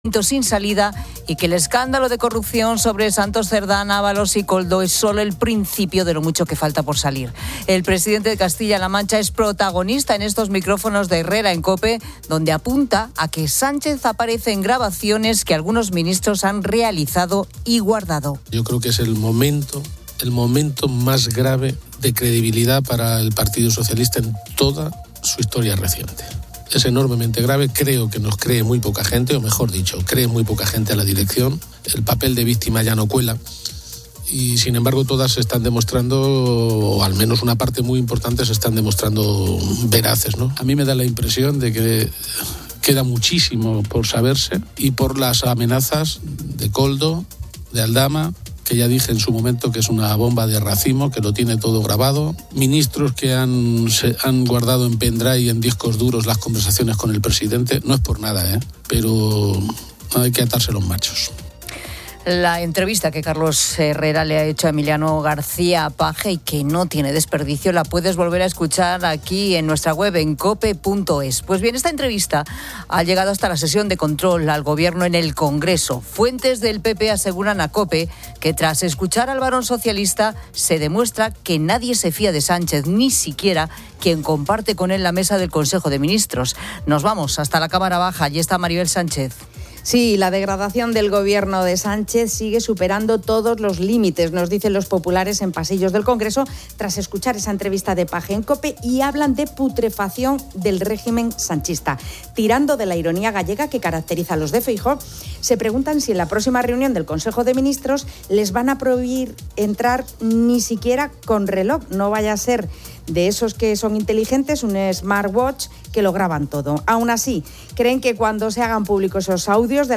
El presidente de Castilla-La Mancha es protagonista en estos micrófonos de Herrera en COPE, donde apunta a que Sánchez aparece en grabaciones que algunos ministros han realizado y guardado. Yo creo que es el momento, el momento más grave de credibilidad para el Partido Socialista en toda su historia reciente.